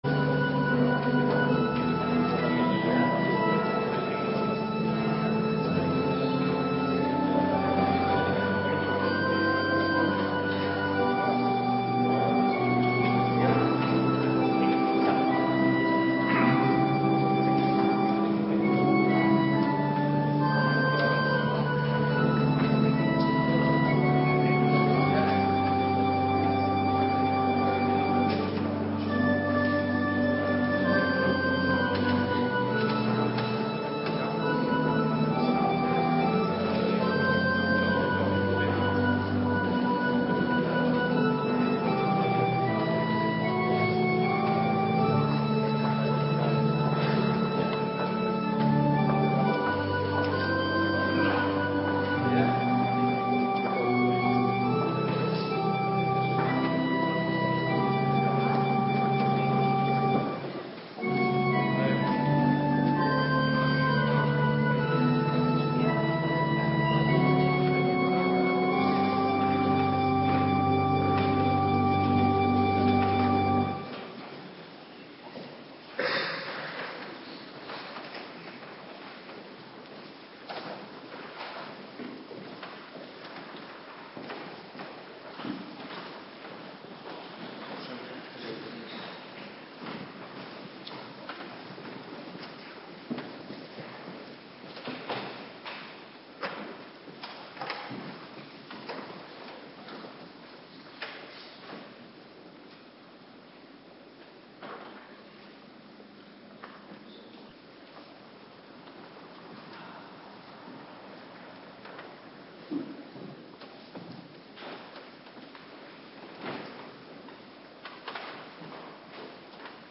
Middagdienst Heilig Avondmaal
Locatie: Hervormde Gemeente Waarder